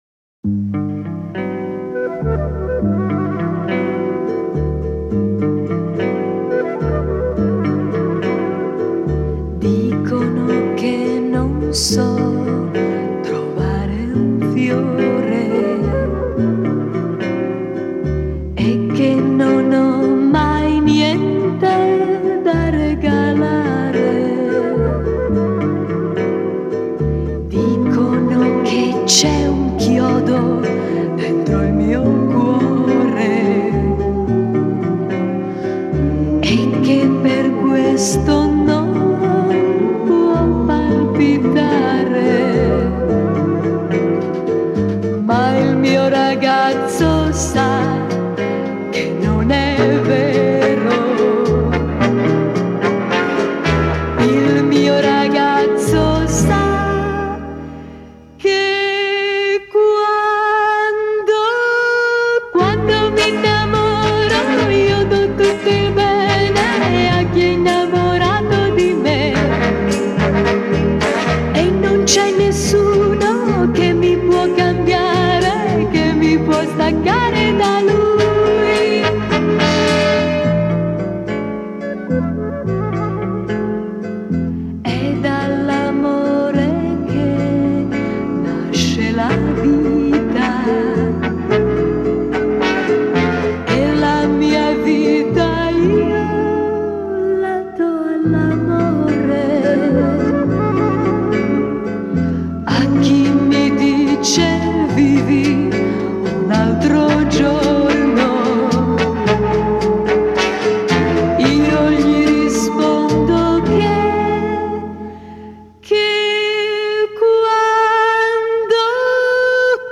Genre: Pop, oldies